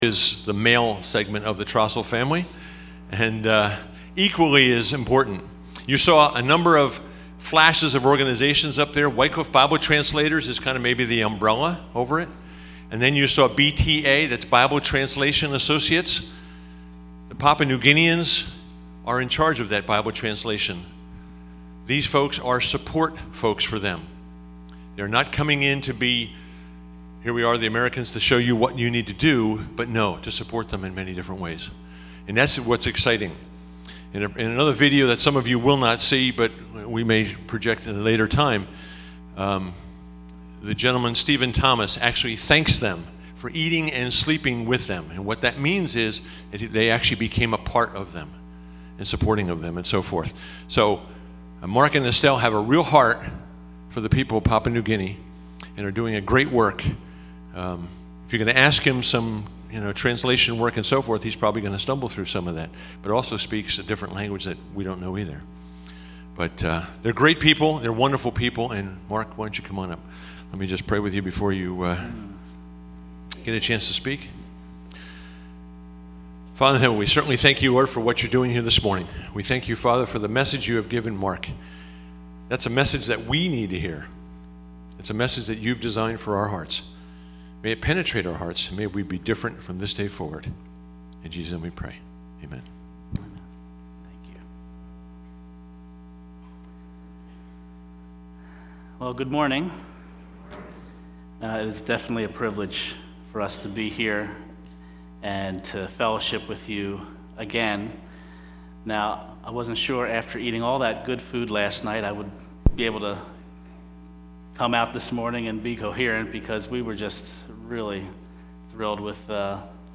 Mission Sunday